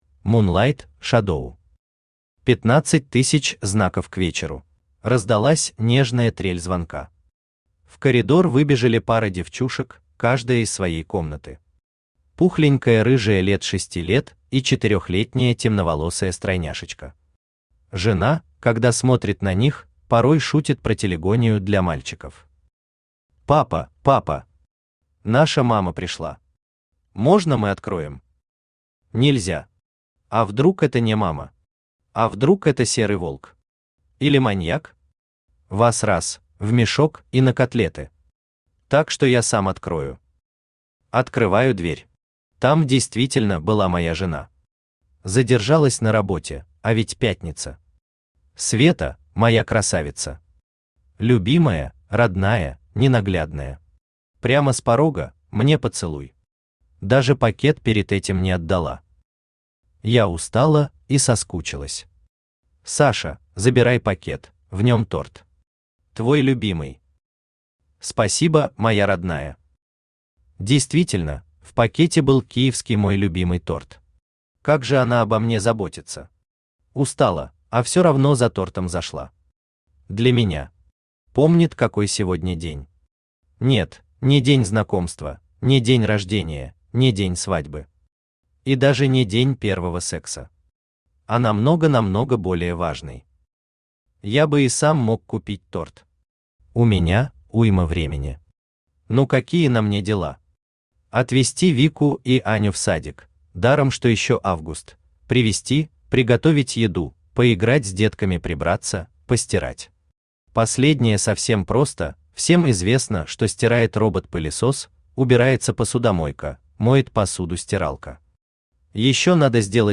Aудиокнига Пятнадцать тысяч знаков к вечеру Автор Мунлайт Шадоу Читает аудиокнигу Авточтец ЛитРес.